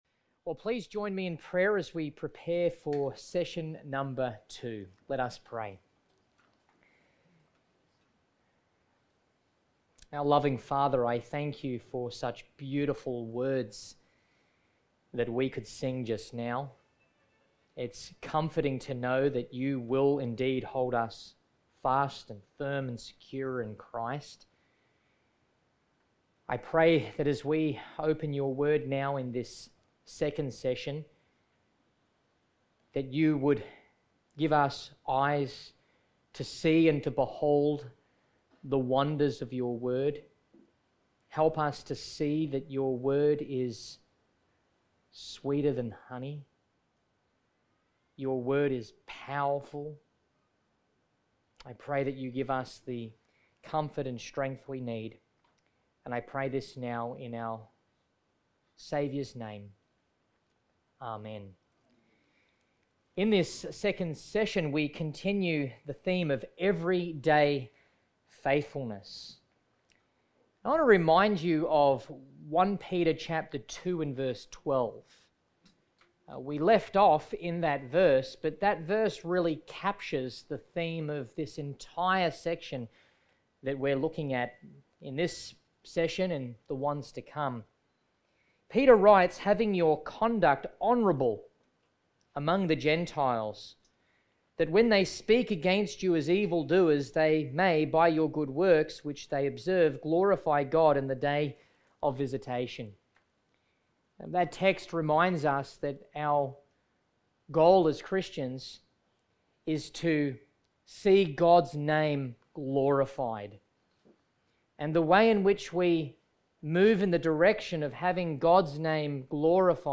Series: GraceWest Camp 2023 Everyday Faithfulness Passage: 1 Peter 2:13-25